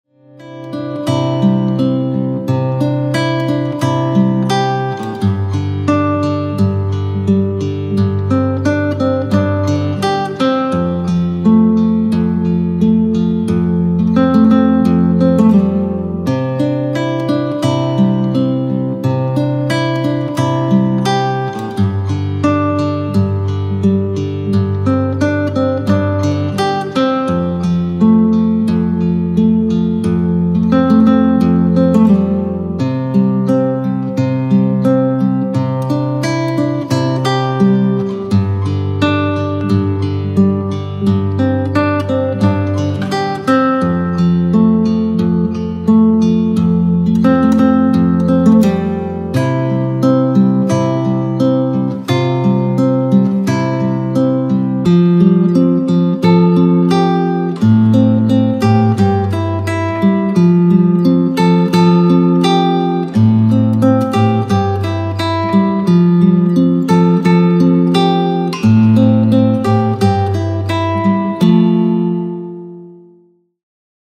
INSTRUMENTAL SAMPLES